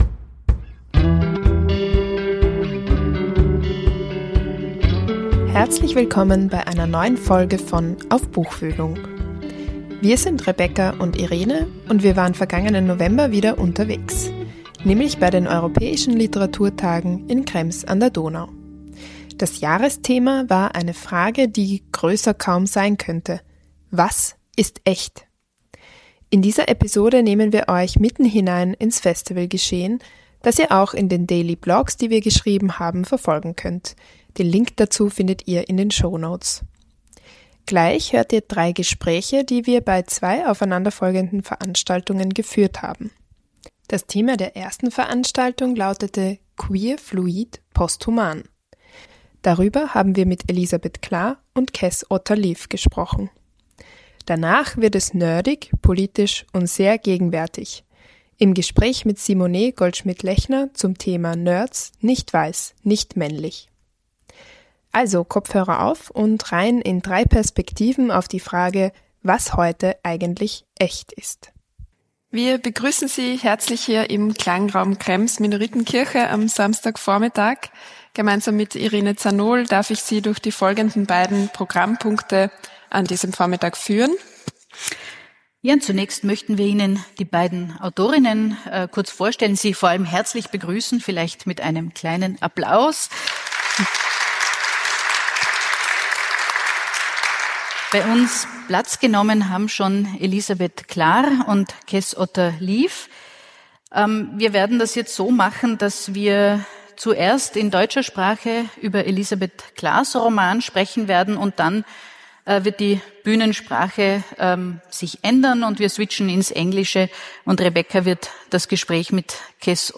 Das Jahresthema war eine Frage, die größer kaum sein könnte: Was ist echt? In dieser Episode nehmen wir euch mitten hinein ins Festivalgeschehen, das ihr auch in den daily blogs, die wir geschrieben haben verfolgen könnt (den Link findet ihr in den Shownotes).